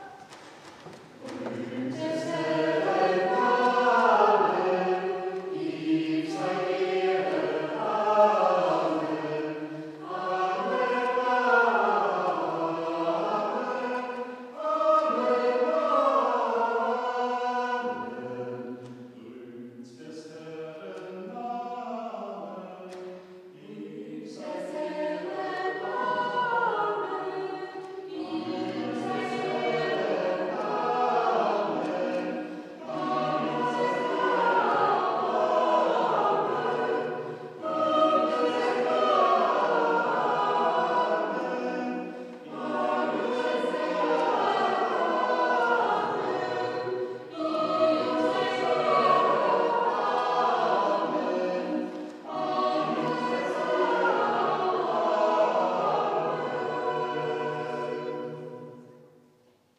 Kanon: Rühmt des Herren Namen... Chor der Evangelisch-Lutherische St. Johannesgemeinde Zwickau-Planitz
Audiomitschnitt unseres Gottesdienstes am 8. Sonntag nach Trinitatis 2023